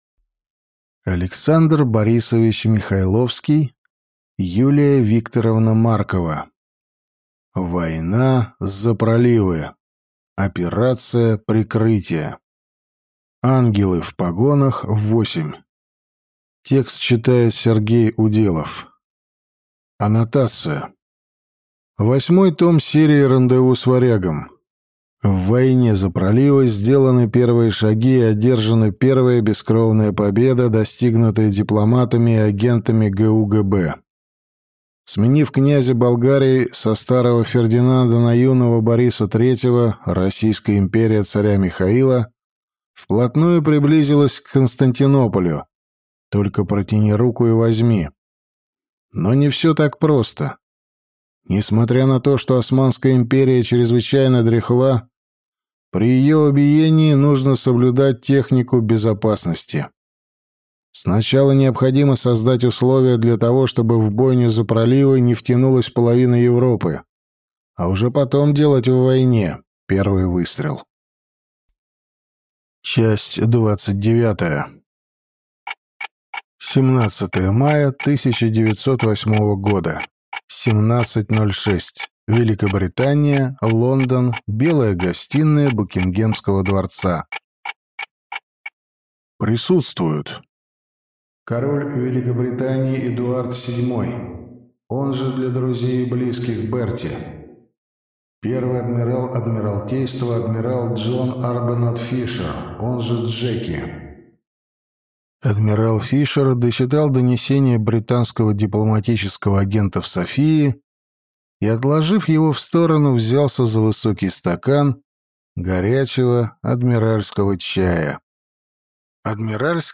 Аудиокнига Война за проливы. Операция прикрытия | Библиотека аудиокниг